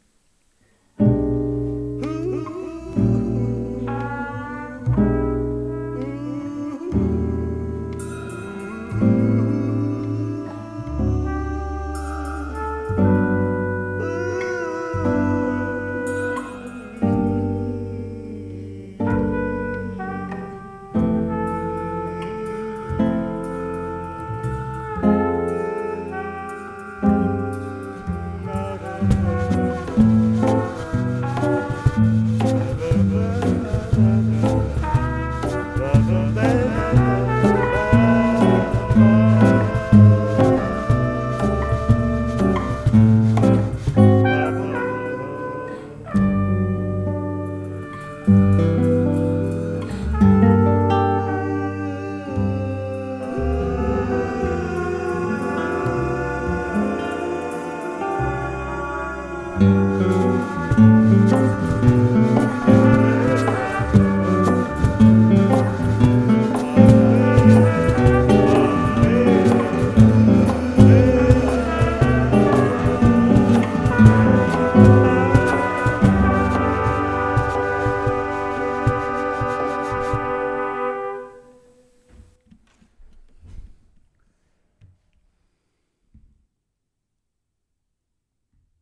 Jazz Interlude.mp3
recorded in Portugal last month
in the Douro Valley
voice, trumpet and percussion